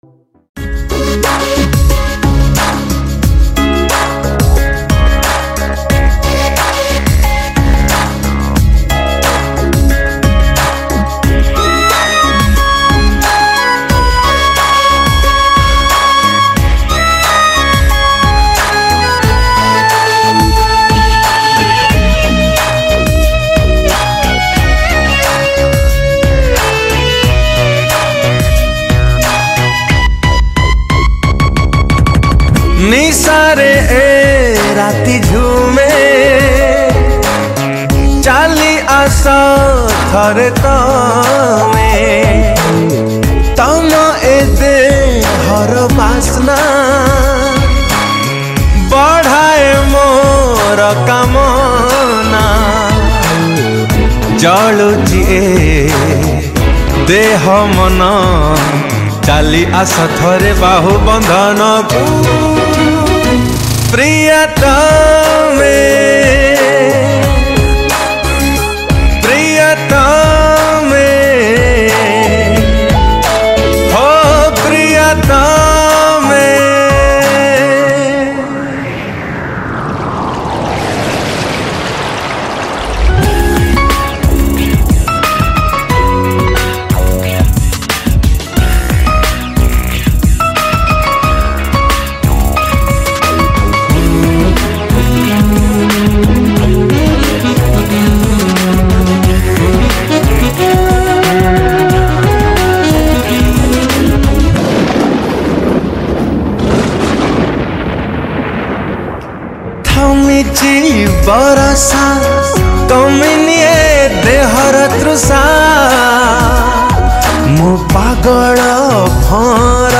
Odia Super Hit Songs